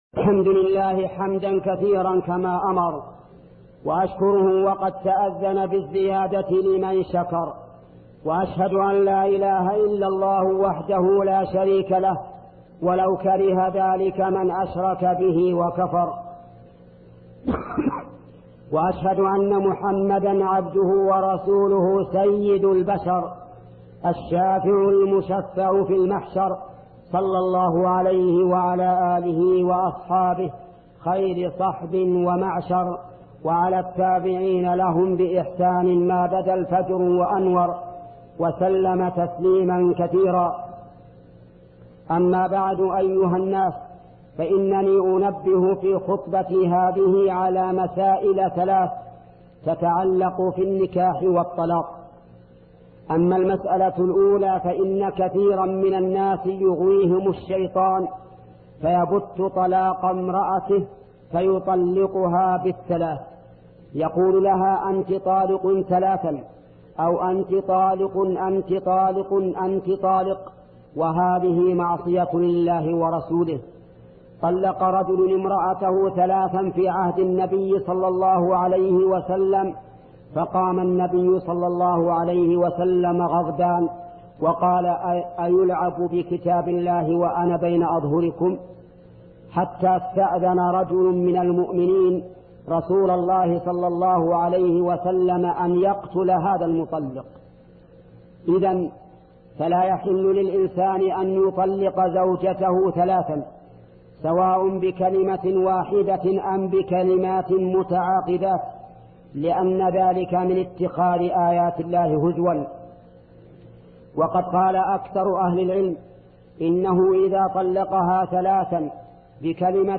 خطبة الجمعة بعنوان ضرر مطالعة المجلات الساقطة